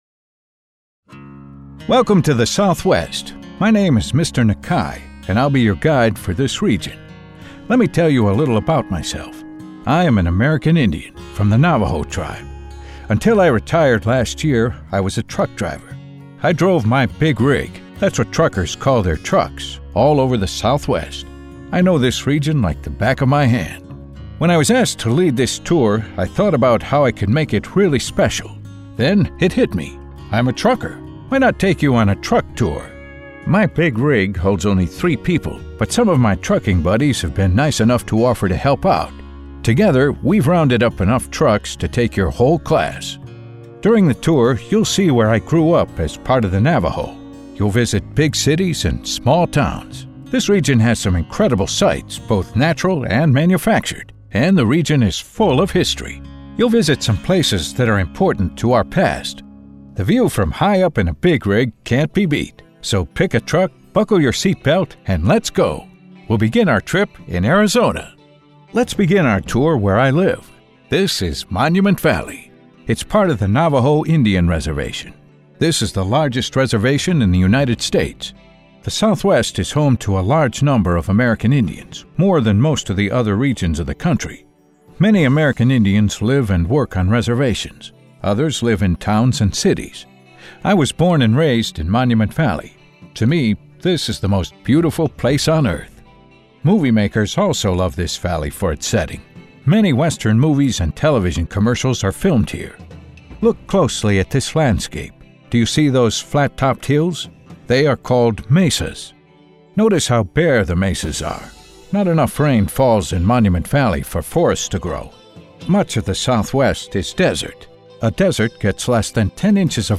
E-Learning: Corporate HR Training
An E-Learning module for new hires. Music: QX 4-01.